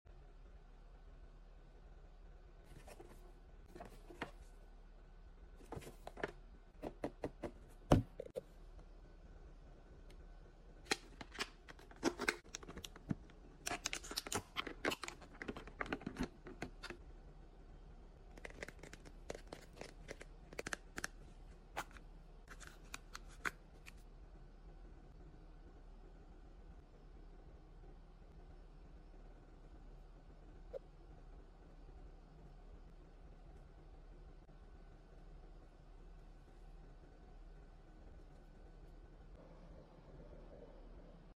ASMR Unboxing Toy Recyclings Recyclies sound effects free download